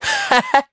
Neraxis-Vox_Happy2_jp.wav